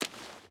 Water Run 5.ogg